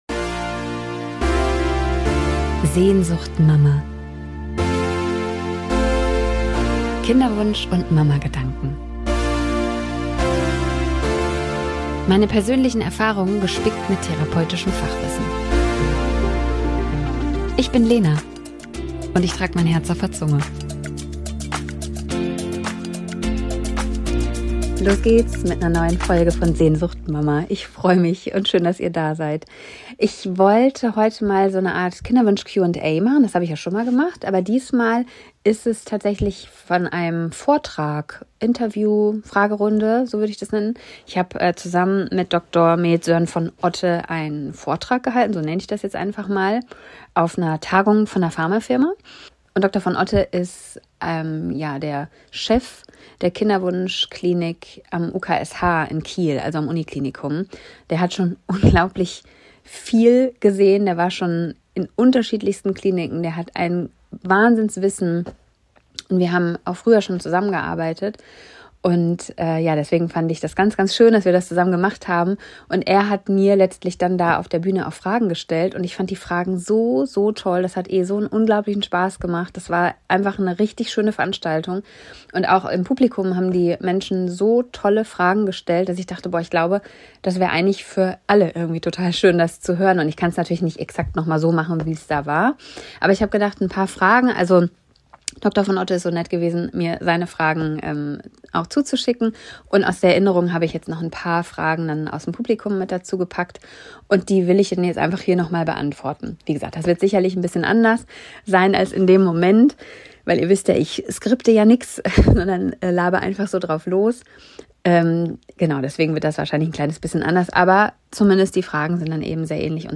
65. Kinderwunsch Fragerunde & Vortrag bei Kiwu Tagung